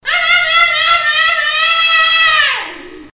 Gaitada Vovó
gaitada-da-vovo.mp3